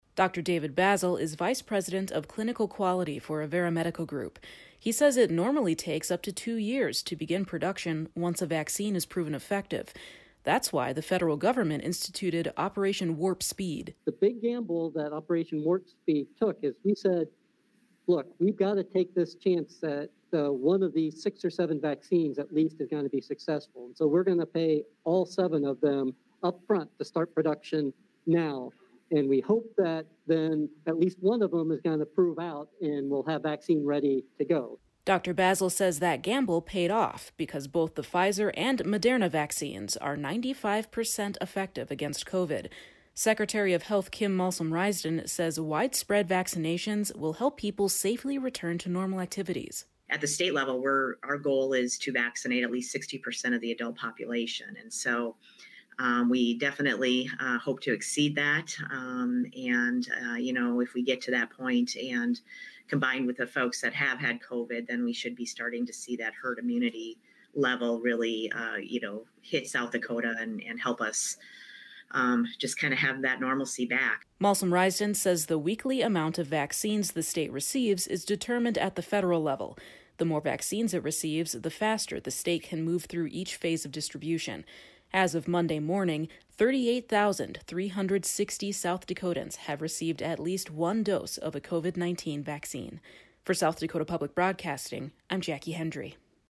The state Secretary of Health and leaders from Sanford and Avera answered questions about the vaccines during a Sioux Falls Downtown Rotary meeting.